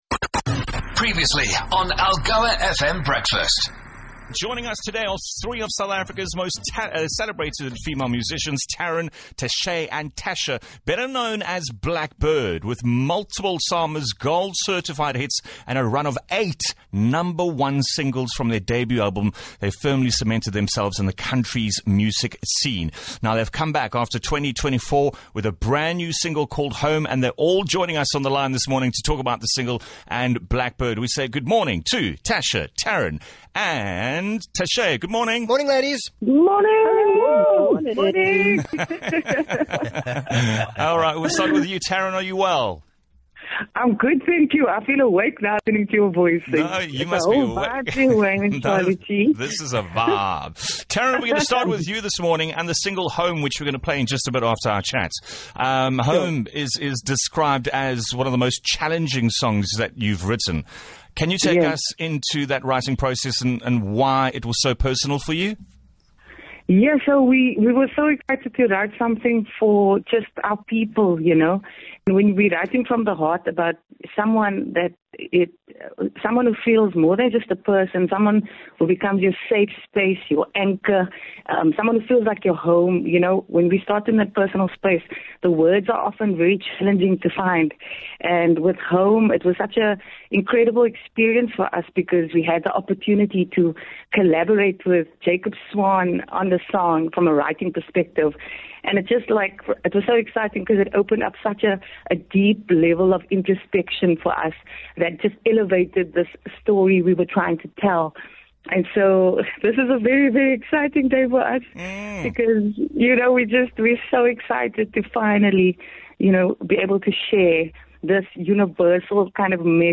Expect thought provoking interviews, heartfelt stories that impact Algoa Country positively and laughter that will set your day off right.